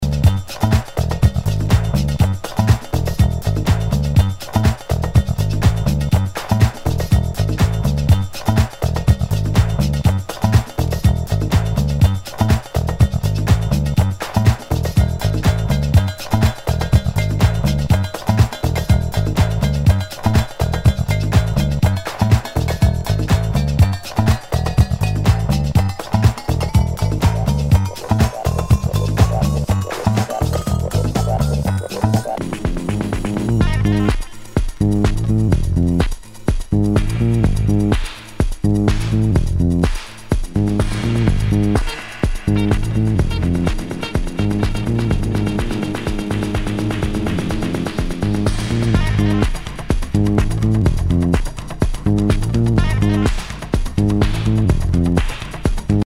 HOUSE/TECHNO/ELECTRO
ナイス！.ディープ・ディスコ・ハウス！